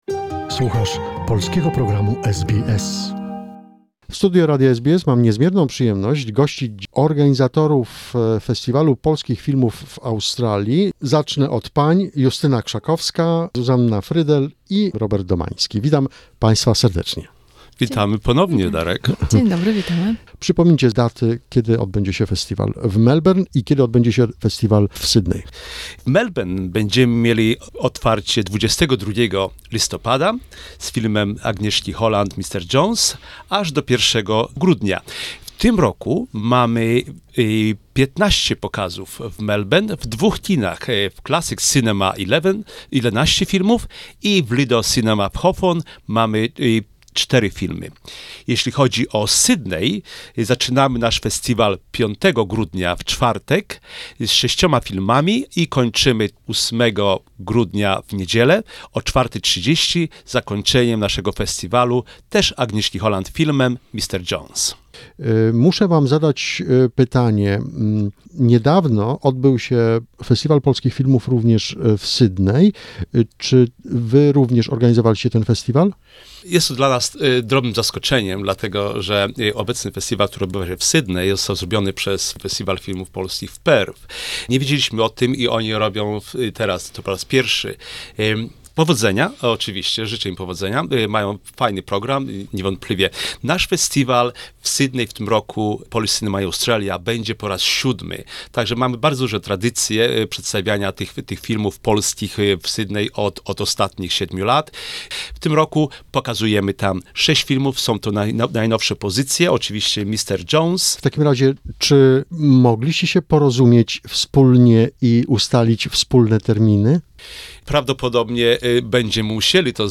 What else will we see during the festival? ... this is the second part of the interview with the organizers.